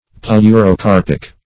Search Result for " pleurocarpic" : The Collaborative International Dictionary of English v.0.48: Pleurocarpic \Pleu`ro*car"pic\, Pleurocarpous \Pleu`ro*car"pous\, a. (Bot.)
pleurocarpic.mp3